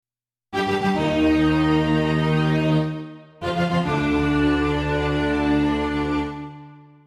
この第一楽章はハ短調(♭三つ)、２／４拍子、ソナタ形式で作曲されていますが、ソナタ形式は次のように《主題提示部》、《展開部》、《再現部》を持った曲の形式のことです。
ここでの第一主題のリズムが「運命がかく戸を叩く」といわれているもので、このリズムによってこの楽章が統一されています。